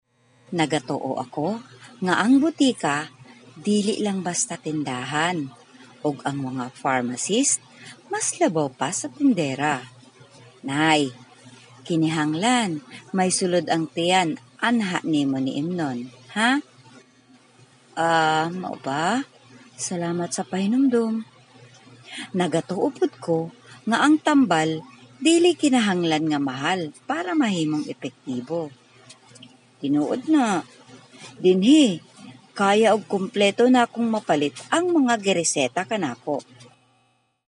CEBUANO FEMALE VOICES
female